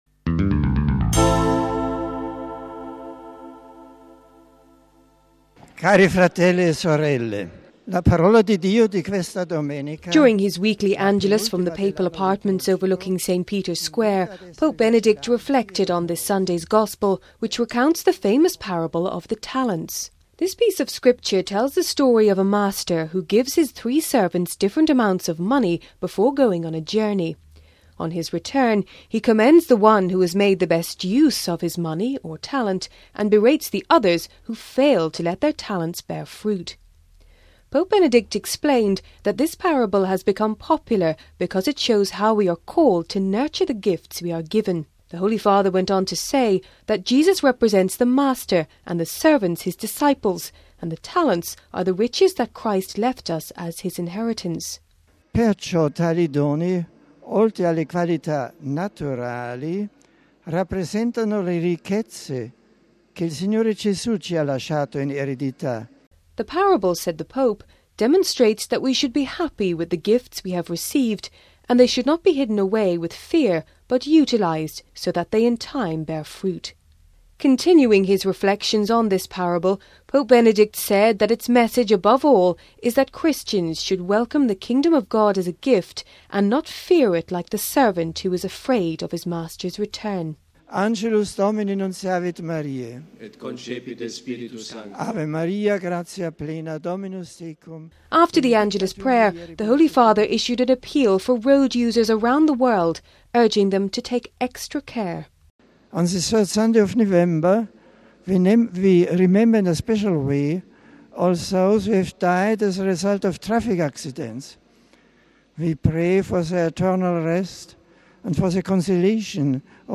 During his weekly Angelus from the Papal Apartments overlooking St Peter’s Square, Pope Benedict reflected on this Sunday’s Gospel which recounts the famous parable of the talents.
After the Angelus prayer the Holy Father issued an appeal for road users around the world urging them to take extra care.